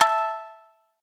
shamisen_ea.ogg